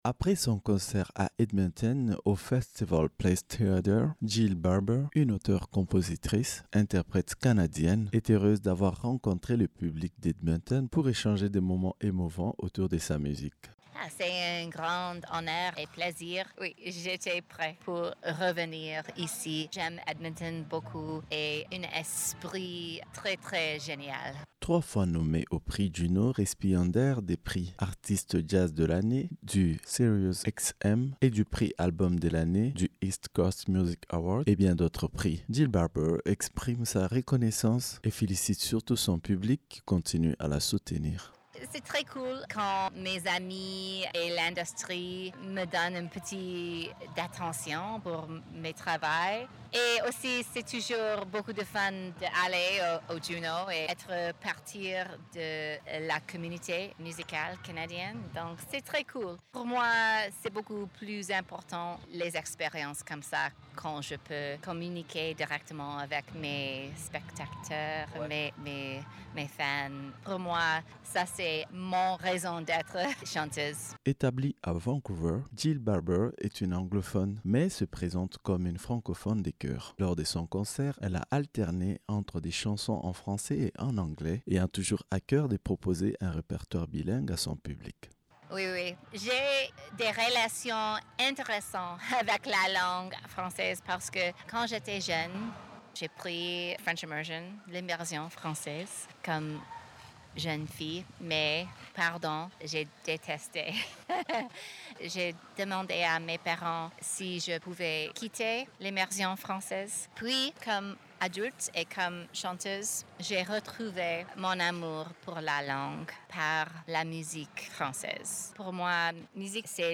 Entrevue-Jill-Barber-fin.mp3